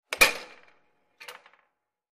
Air Hockey; Puck Hit Into Goal.